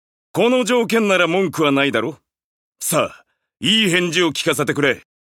「エディットモード」で男性用追加音声が使用可能になります。